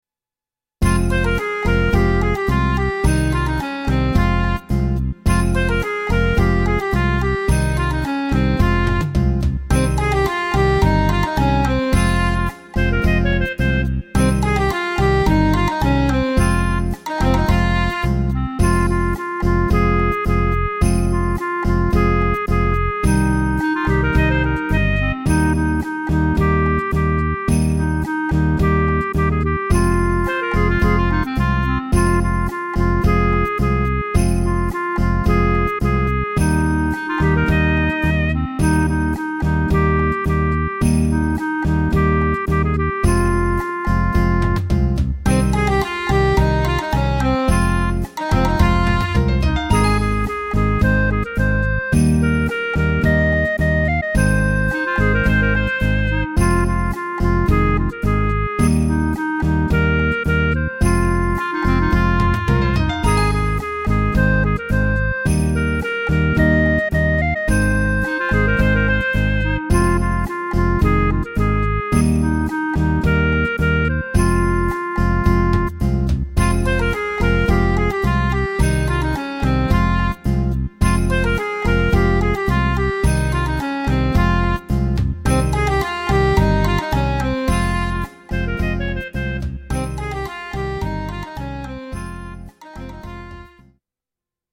Greek Oriental Music-Syrtos